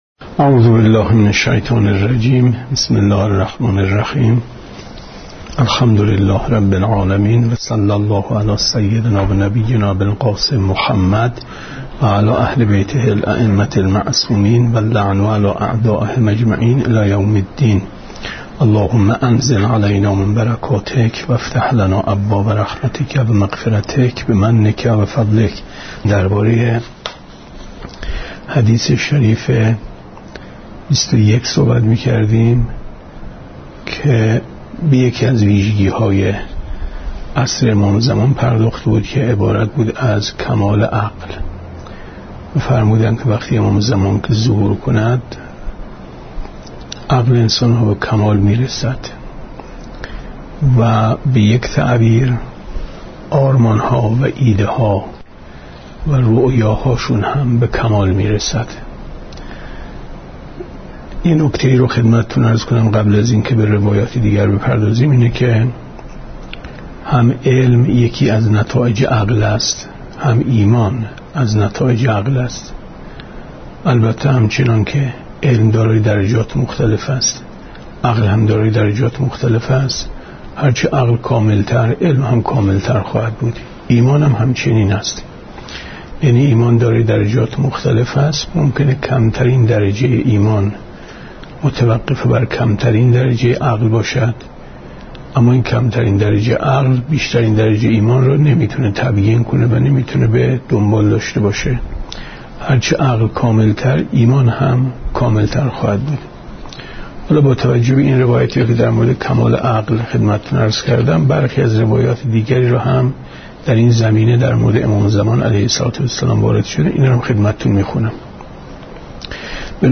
گفتارهای ماه مبارک رمضان 1436 ـ جلسه پانزدهم ـ 19/ 4/ 94 ـ شب بیست و چهارم ماه رمضان